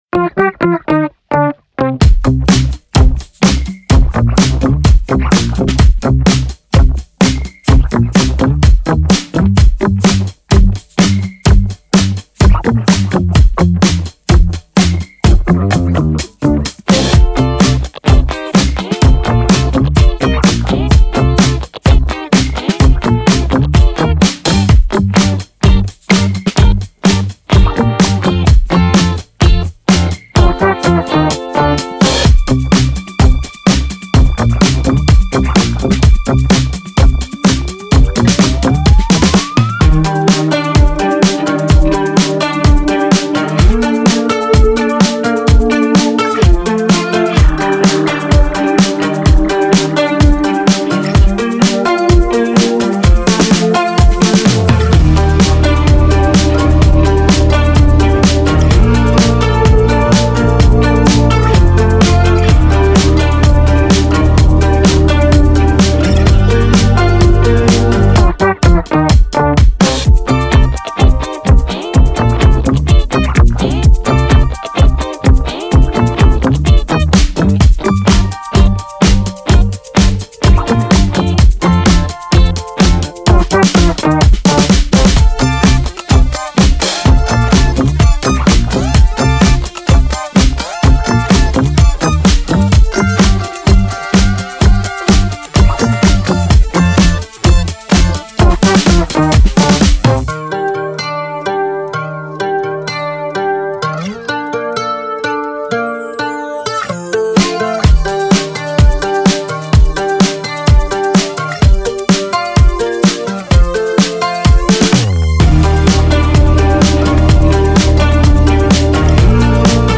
official instrumental
Pop Instrumentals